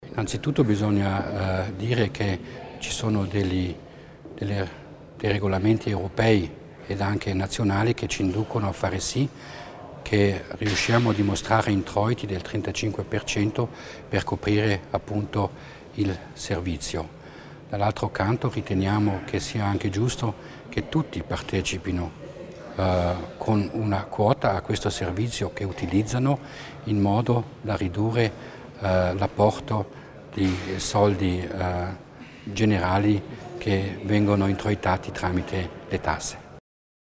L'Assessore Mussner spiega le novità in tema di trasporto pubblico